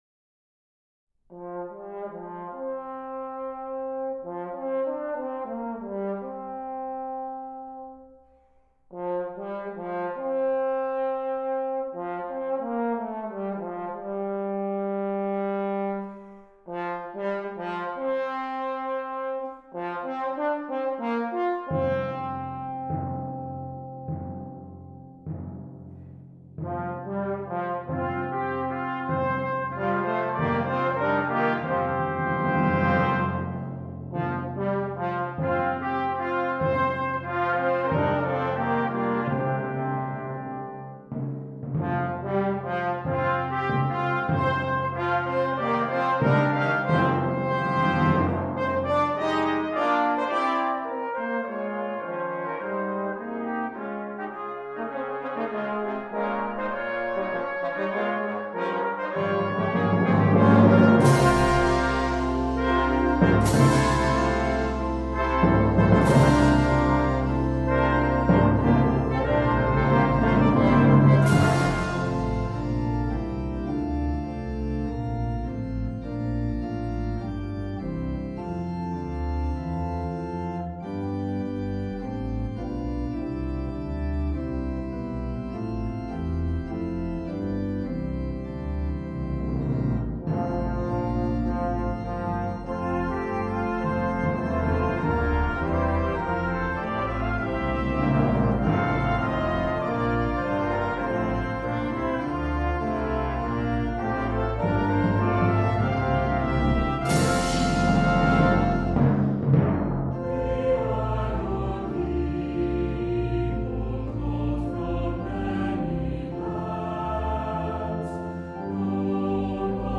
Voicing: Assembly,SATB